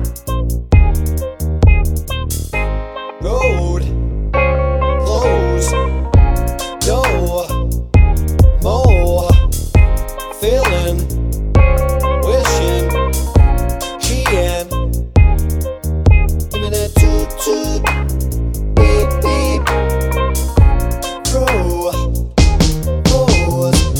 Remix With No End Backing Vocals Pop (1990s) 3:01 Buy £1.50